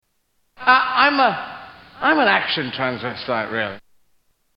Tags: Comedians Eddie Izzard Eddie Izzard Soundboard Eddie Izzard Clips Stand-up Comedian